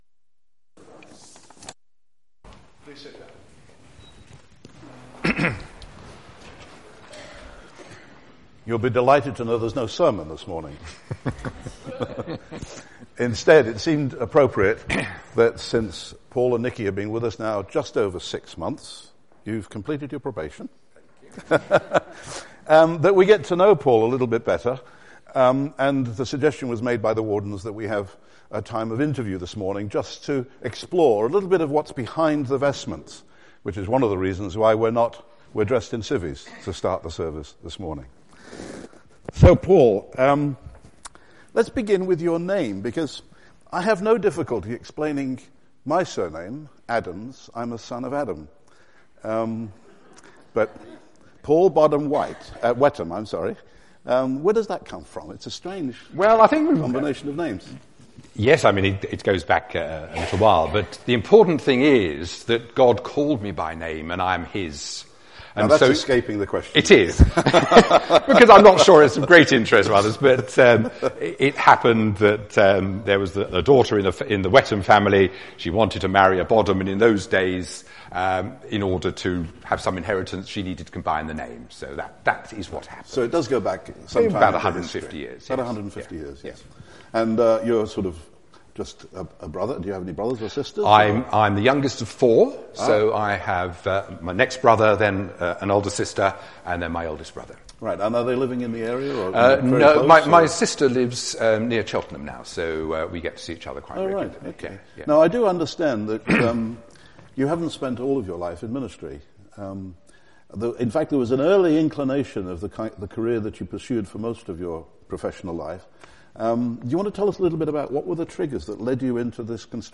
This is not a sermon!